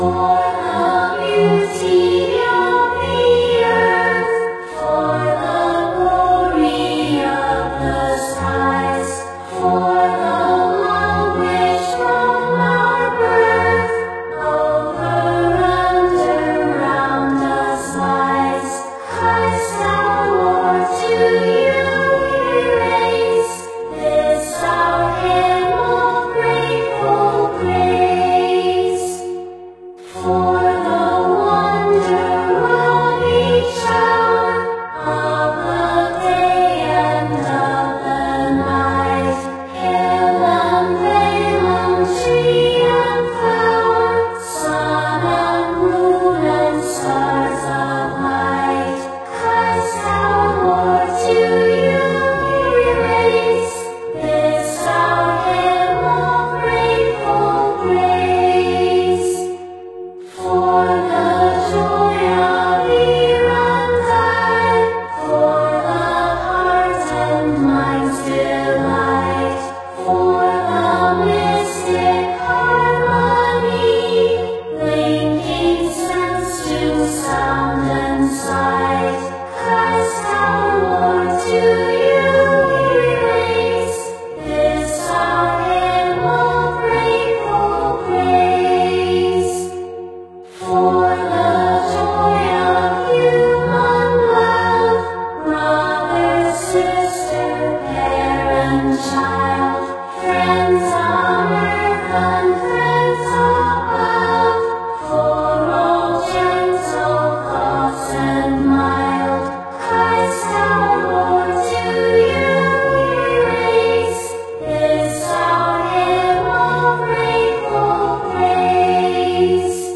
🎼 Choral Arrangements Demo
Voices used:
Akari Kizuna (RVC), Eleanor_Forte_AI (mostly), MEDIUM5Stardust (mostly), and ASTERIAN (mostly)
choir.m4a